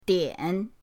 dian3.mp3